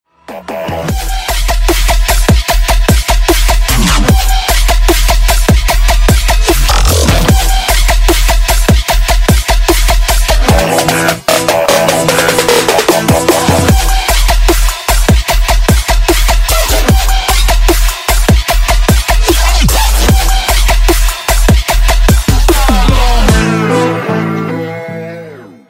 Ремикс # Электроника